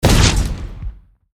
academic_skill_minethrower_02_fire.ogg